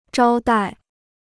招待 (zhāo dài) พจนานุกรมจีนฟรี